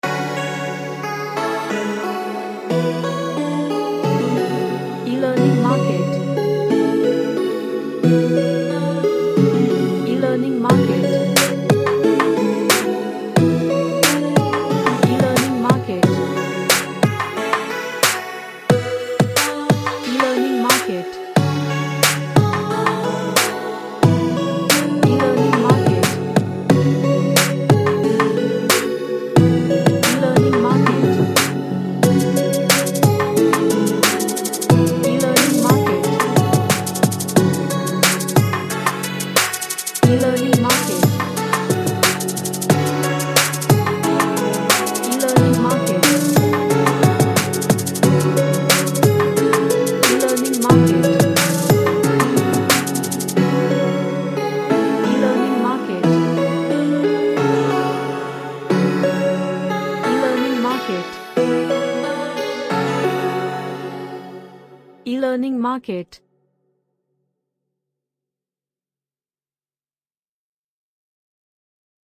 An R&B track with 90s synths
Chill Out